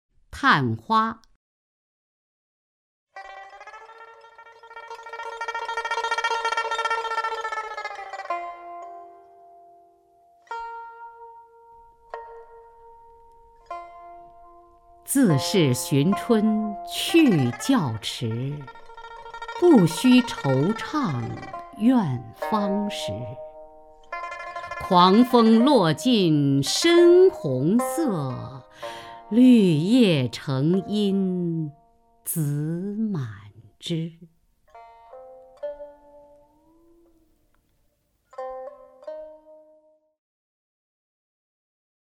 雅坤朗诵：《叹花》(（唐）杜牧) （唐）杜牧 名家朗诵欣赏雅坤 语文PLUS